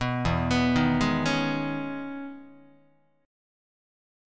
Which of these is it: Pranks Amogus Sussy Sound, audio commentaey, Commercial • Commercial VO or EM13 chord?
EM13 chord